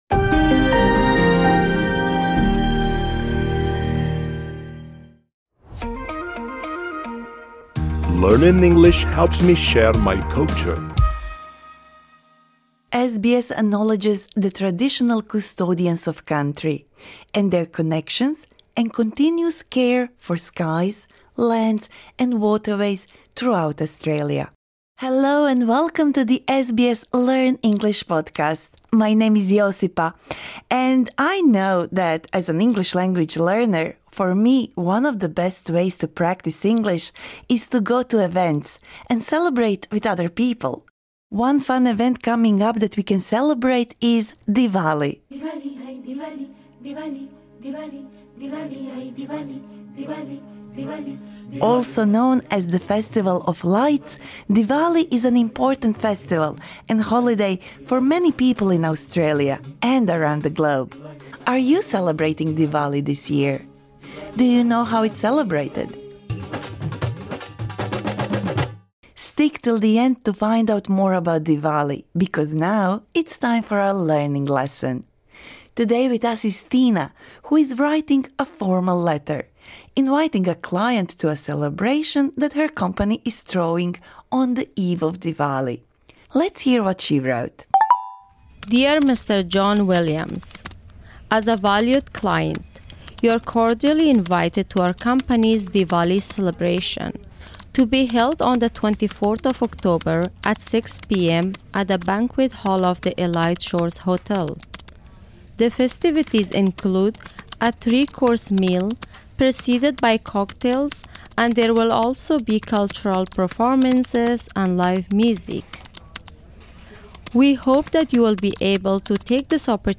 This lesson suits upper-intermediate to advanced learners.